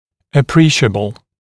[ə’priːʃəbl][э’при:шэбл]существенный, значительный, заметный